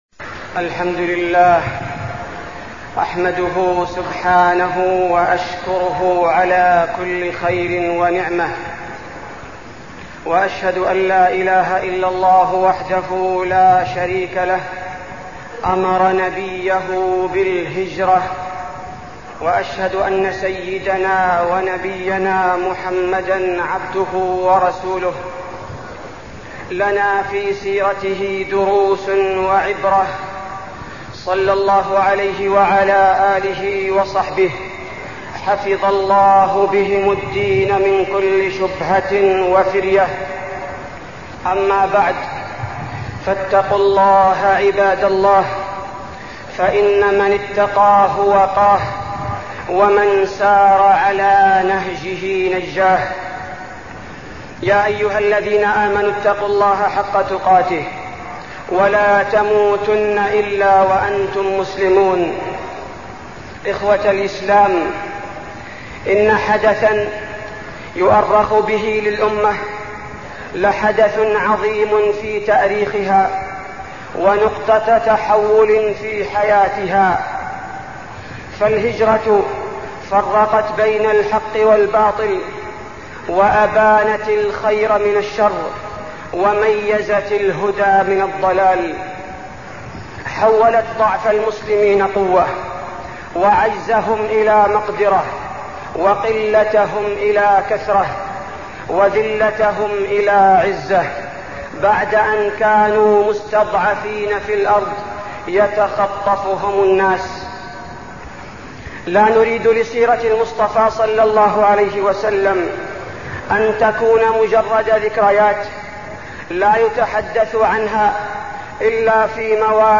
تاريخ النشر ١٨ محرم ١٤١٦ هـ المكان: المسجد النبوي الشيخ: فضيلة الشيخ عبدالباري الثبيتي فضيلة الشيخ عبدالباري الثبيتي الهجرة النبوية